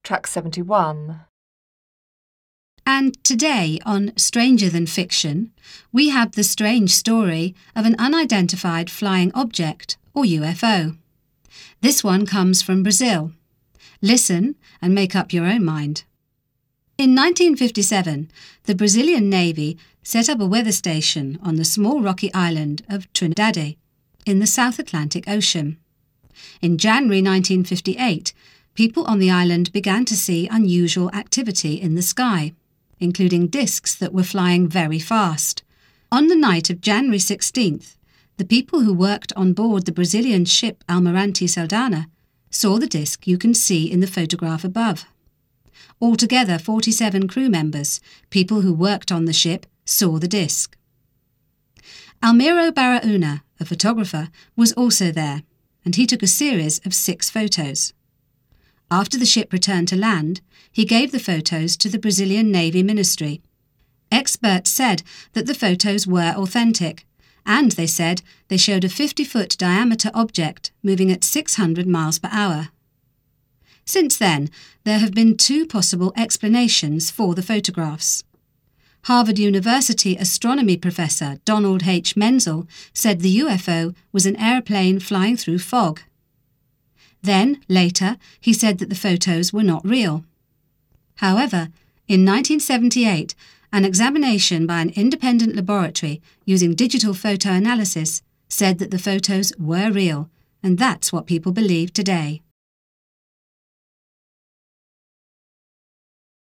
You have 30 seconds to read the task. Then listen to the radio programme and tick off the correct answer.
Sie hören eine Radiosendung.